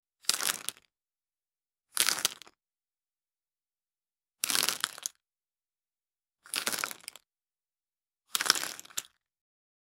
Royalty-free dice sound effects
single-die-roll-2j47i2vc.wav